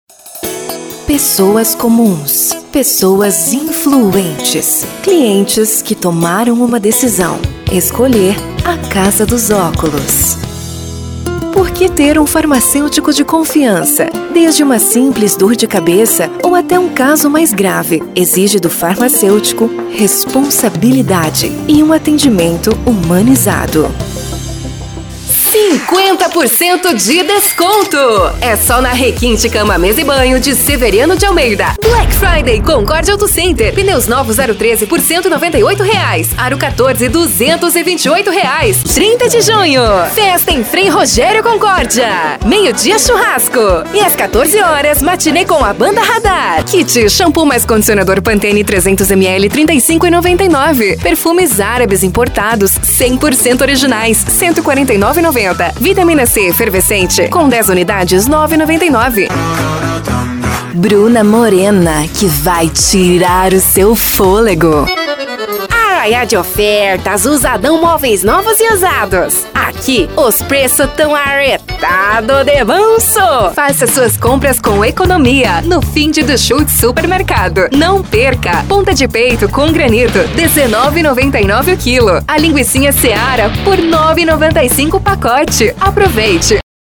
Spot Comercial
Vinhetas
Padrão
Impacto
Animada
Caricata